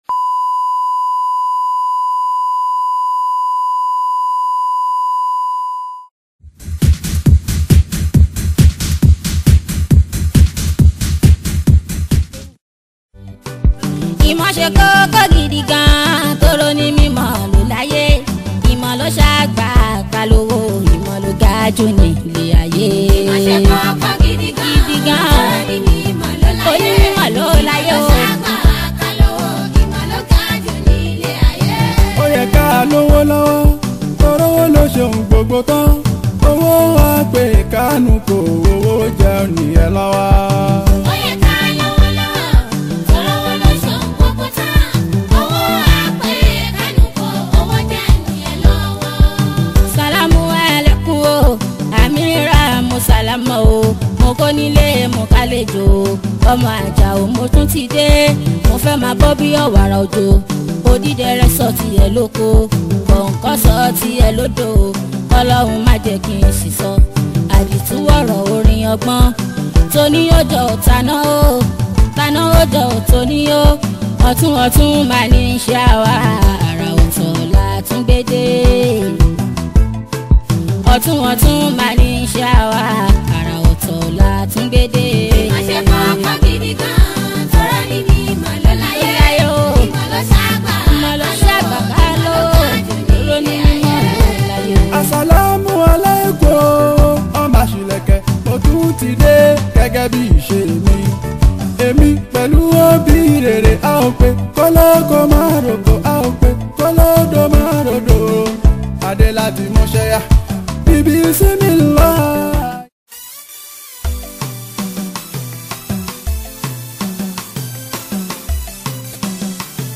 Yoruba Islamic song
Nigerian Yoruba Fuji track
Fuji Music
be ready to dance to the beats